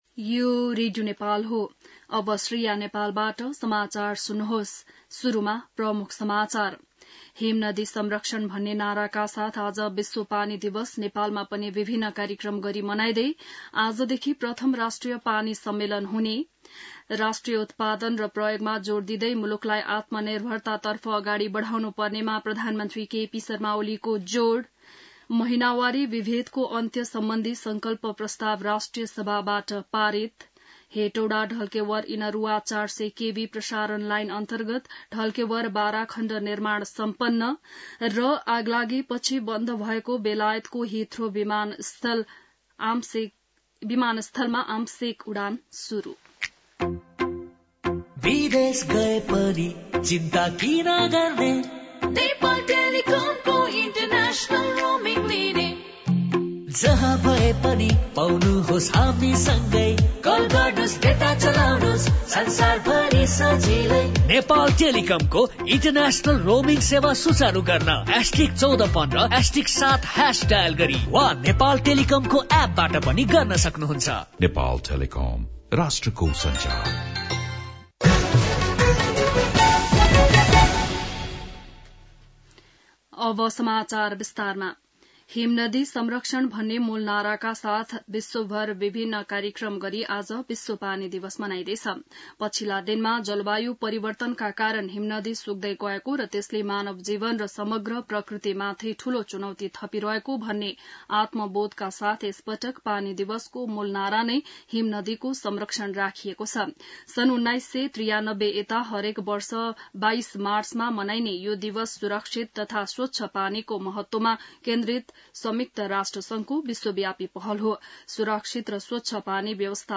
बिहान ७ बजेको नेपाली समाचार : ९ चैत , २०८१